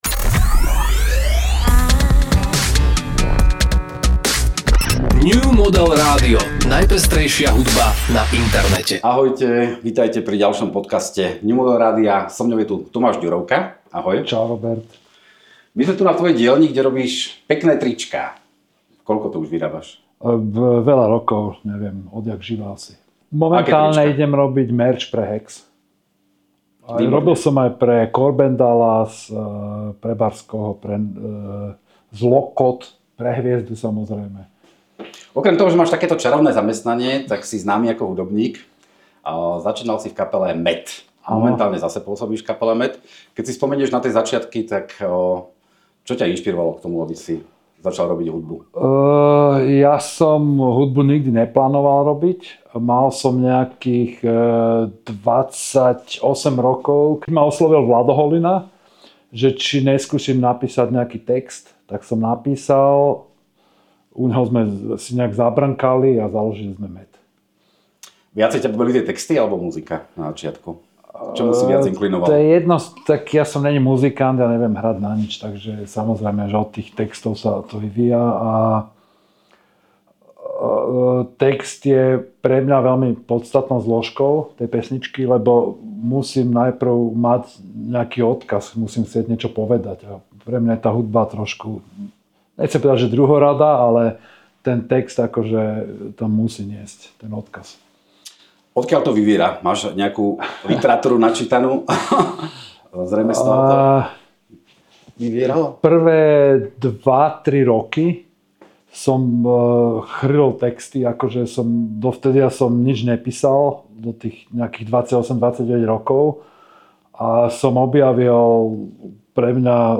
Na streamovacie služby pribudol ďalší redakčný rozhovor New Model Rádia.